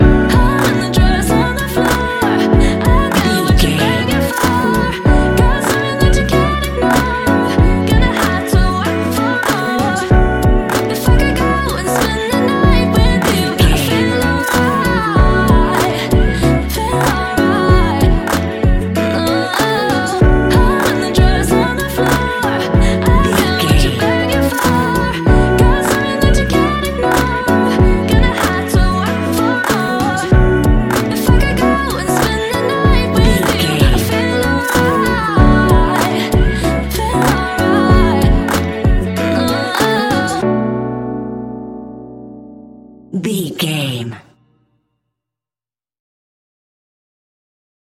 Ionian/Major
F♯
chilled
laid back
Lounge
sparse
new age
chilled electronica
ambient
atmospheric